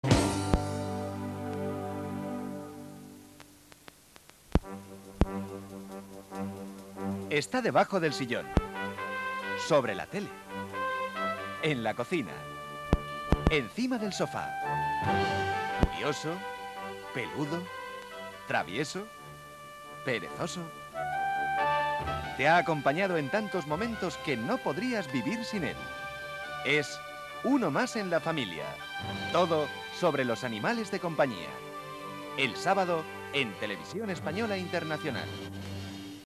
1 Este documento es un anuncio para un programa. volver a las preguntas